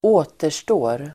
Uttal: [²'å:ter_stå:r]